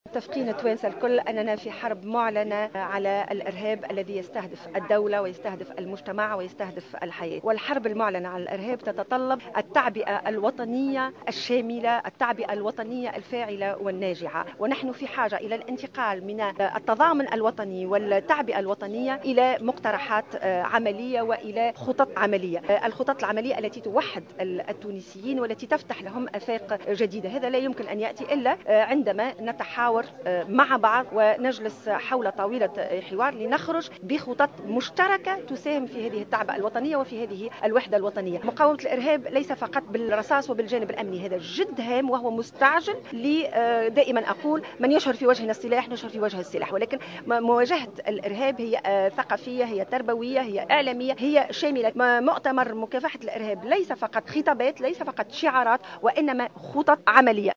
طالبت تنسيقية أحزاب المعارضة خلال ندوة صحفية عقدتها اليوم الأربعاء 02 ديسمبر 2015 بعقد مؤتمر وطني حول مكافحة الارهاب يوم 17 ديسمبر الجاري، تتوج أعماله بصياغة ميثاق أو خطة وطنية شاملة لمقاومة هذه الظاهرة التي باتت تهدد أمن البلاد واستقرارها.